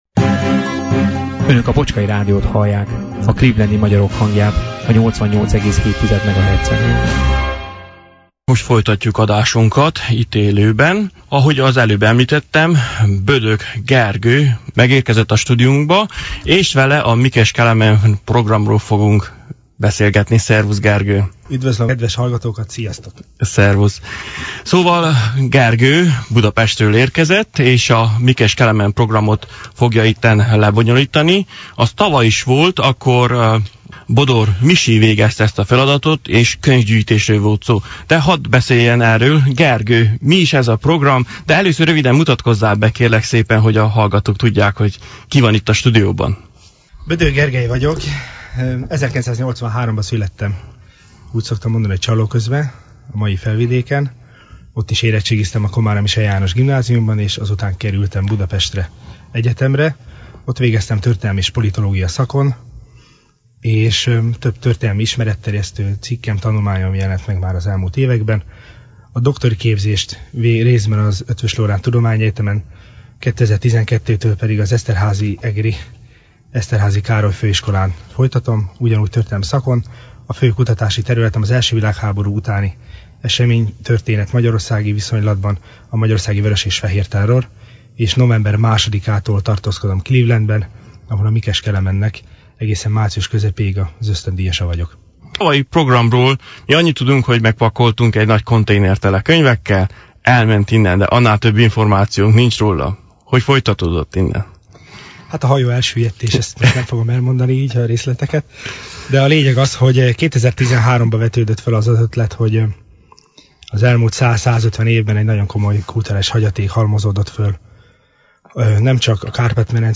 Stúdióvendégünk